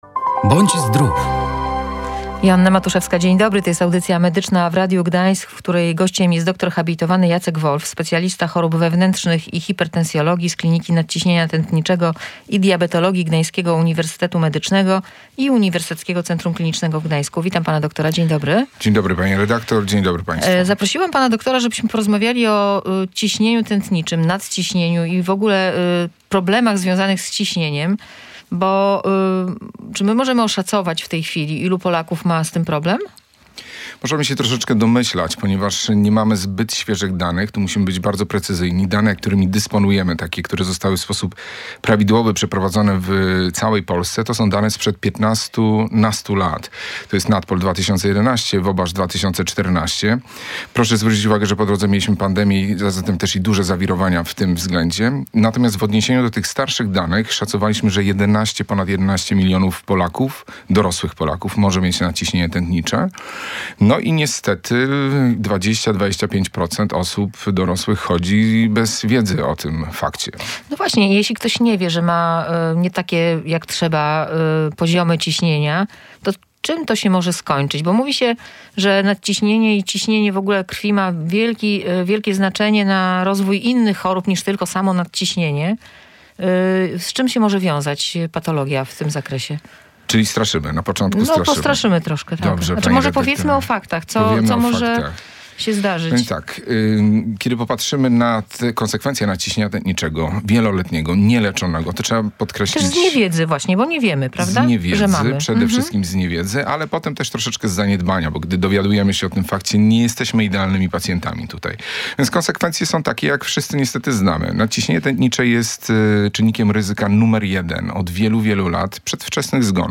w audycji „Bądź Zdrów”